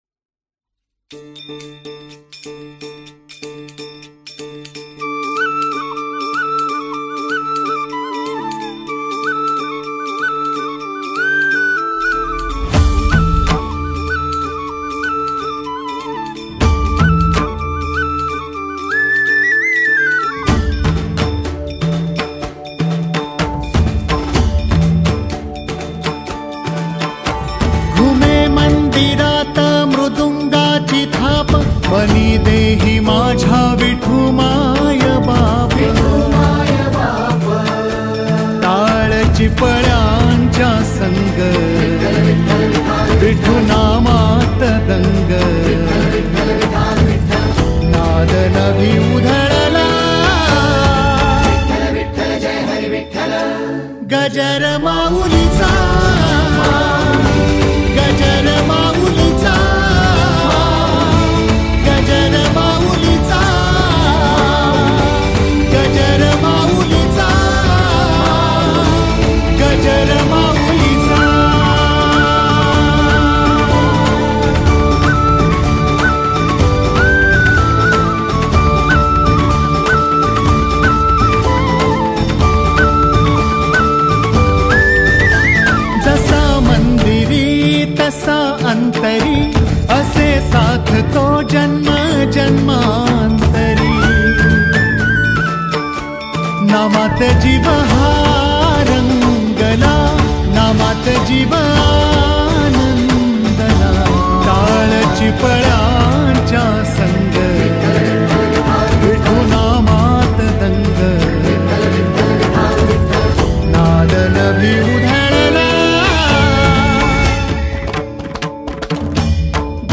devotional kirtan
energetic and soulful voice
Devotional Songs